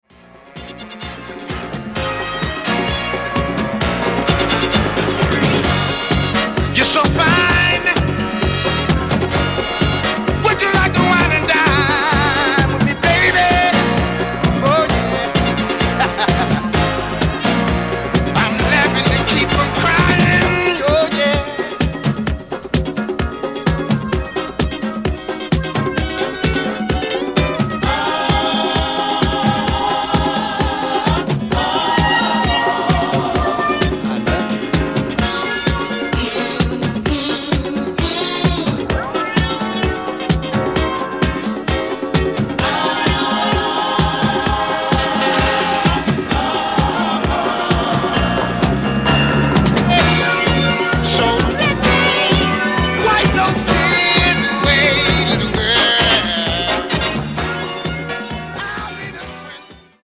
Funk. Soul. R&B. Blues. Gospel